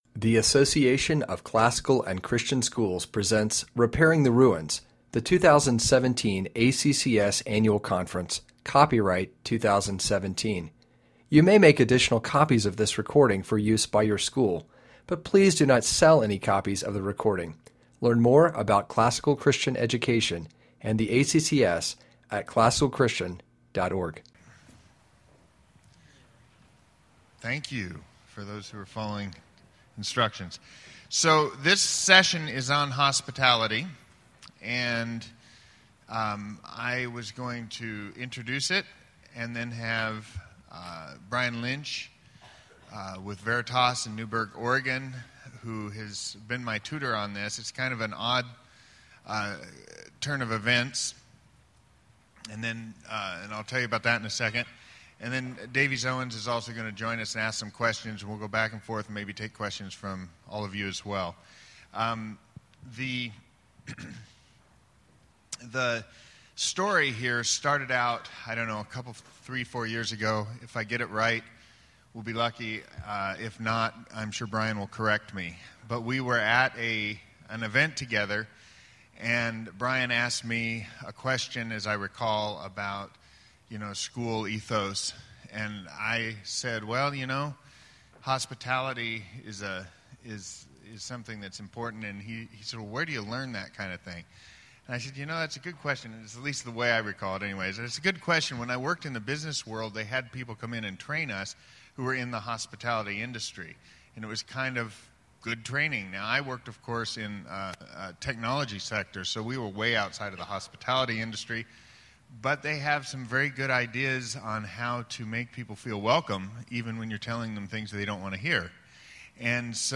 2017 Leaders Day Talk | 0:47:20 | All Grade Levels, Marketing & Growth